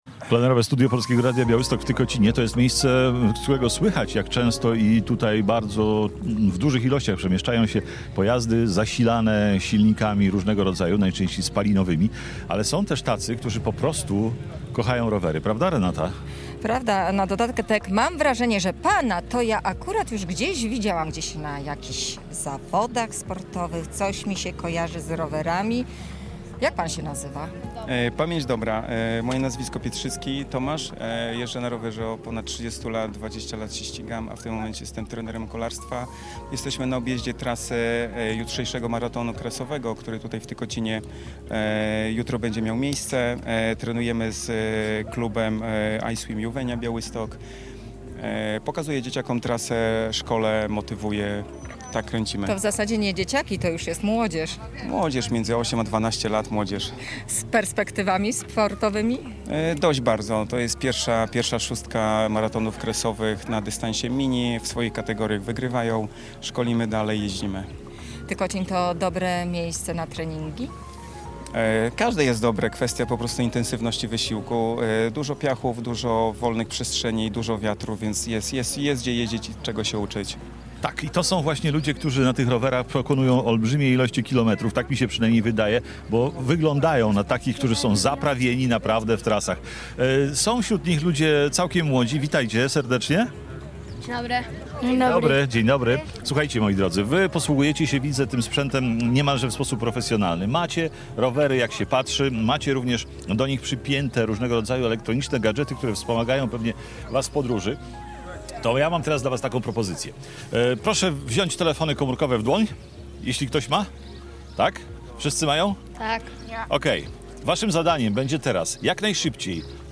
W sobotę, 3 maja właśnie z tego miasteczka program nadawało Polskie Radio Białystok.
Nasz program z Tykocina nadawaliśmy od 14:00 do 18.00. W naszym plenerowym studiu na Placu Czarnieckiego nasi słuchacze mogli podpatrzeć pracę dziennikarzy, ale także wziąć udział w wielu konkursach, które przygotowaliśmy.